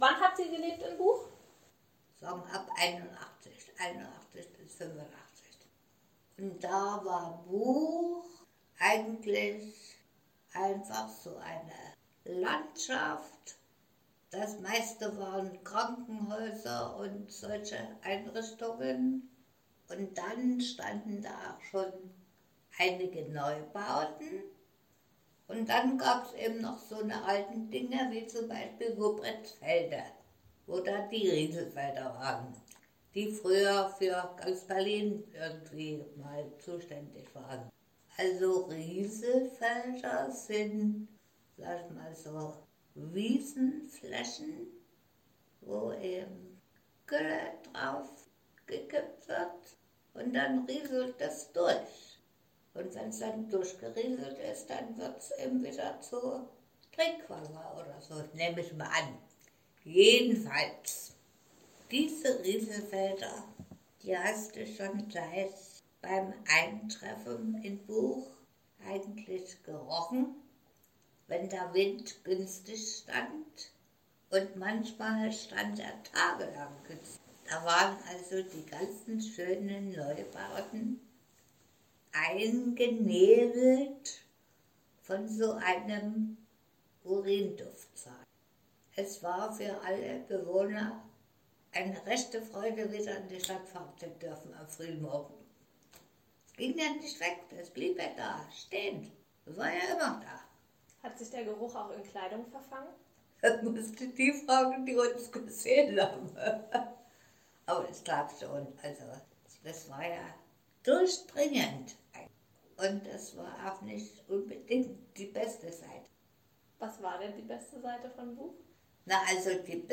Wie damals die Wahrnehmung der Bevölkerung war und wie genau das Leben in Buch der 1980er Jahre gewesen ist, wird im folgenden Zeitzeugeninterview (Audiodatei Dauer 18'25") erzählt.
Langinterview.mp3